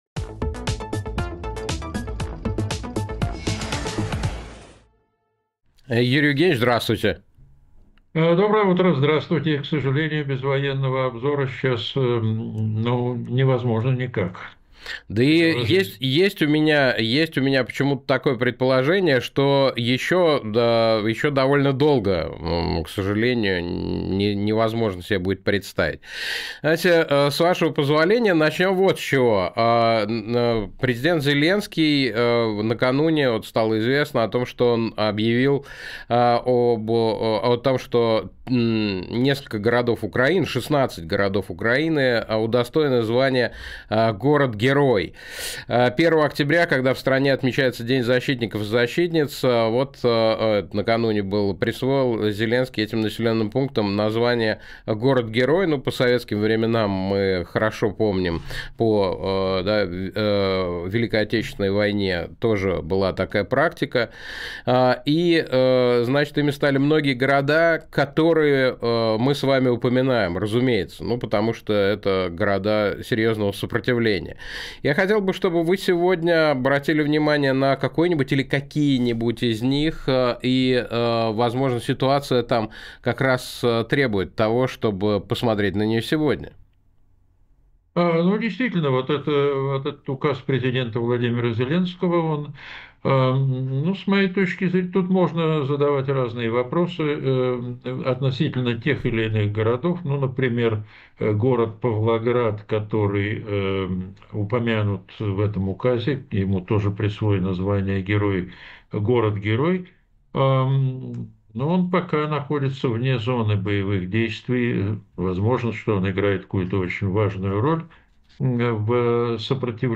Фрагмент эфира от 2 октября